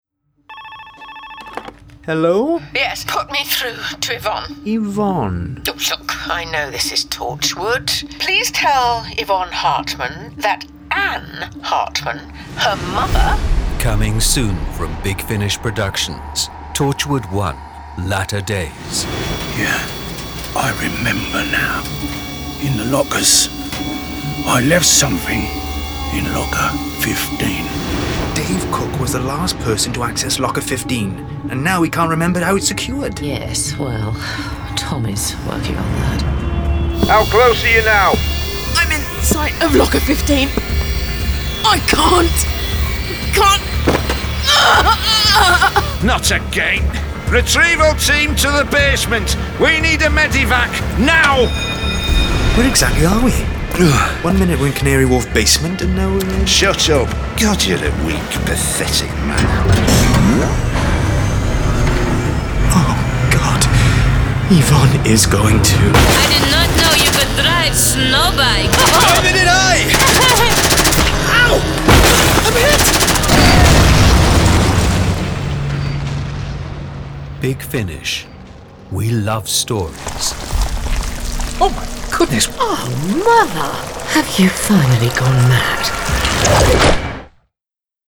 Award-winning, full-cast original audio dramas
Torchwood: Torchwood One: Latter Days Released September 2019 Written by Matt Fitton Gareth David-Lloyd Tim Foley Starring Tracy-Ann Oberman Gareth David-Lloyd This release contains adult material and may not be suitable for younger listeners. From US $25.24 CD + Download US $31.55 Buy Download US $25.24 Buy Save money with a bundle Login to wishlist 38 Listeners recommend this Share Tweet Listen to the trailer Download the trailer